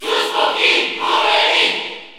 Lemmy_Cheer_French_PAL_SSBU.ogg.mp3